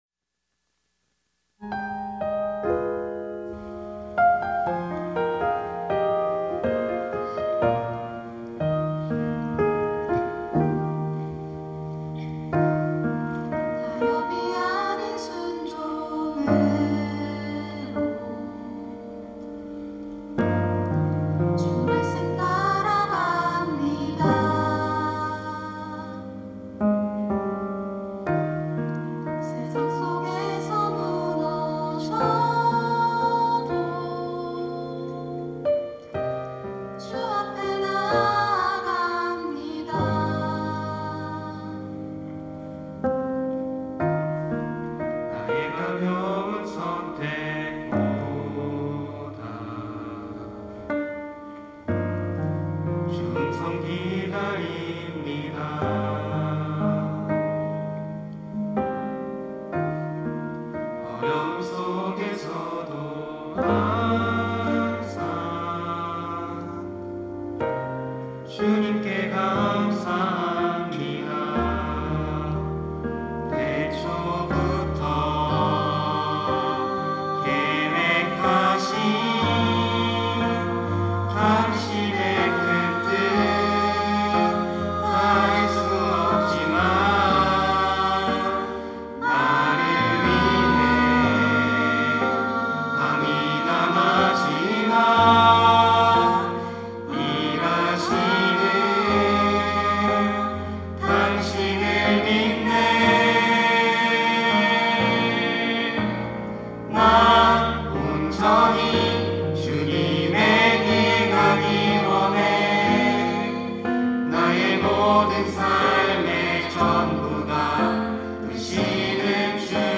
특송과 특주 - 사용하여 주소서